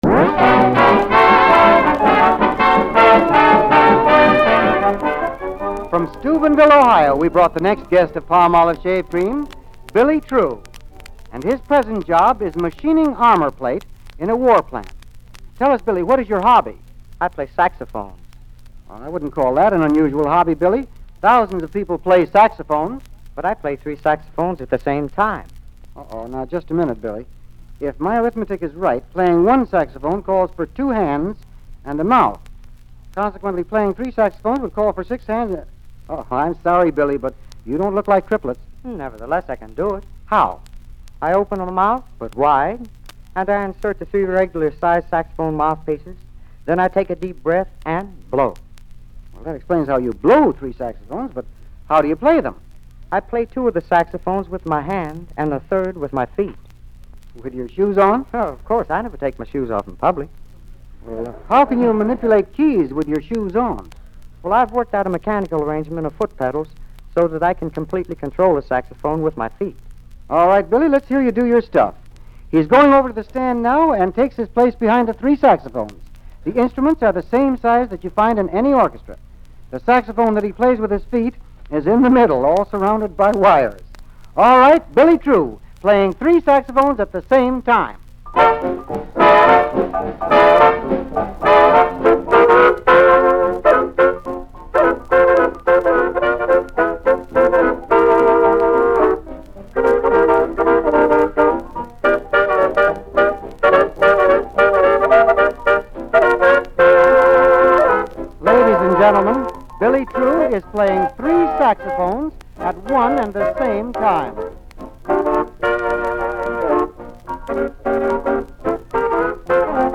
Radio Broadcasts